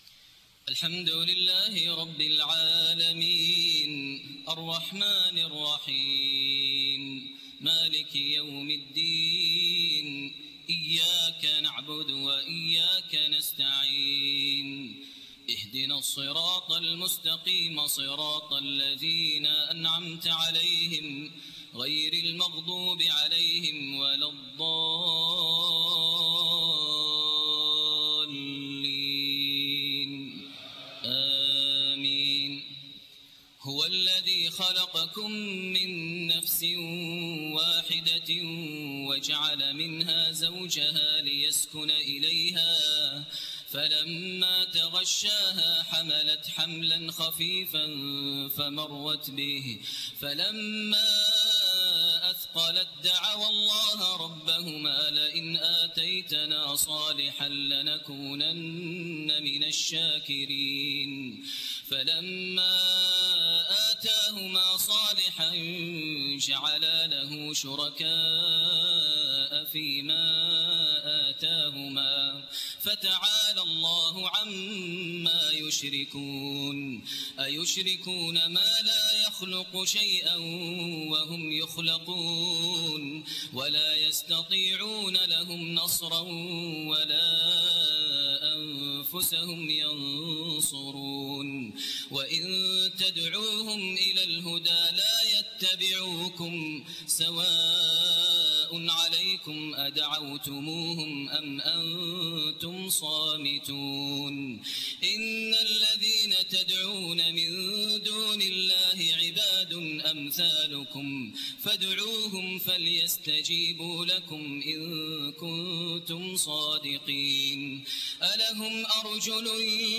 تهجد ليلة 29 رمضان 1429هـ من سورتي الأعراف (189-206) و الأنفال (1-40) Tahajjud 29 st night Ramadan 1429H from Surah Al-A’raf and Al-Anfal > تراويح الحرم المكي عام 1429 🕋 > التراويح - تلاوات الحرمين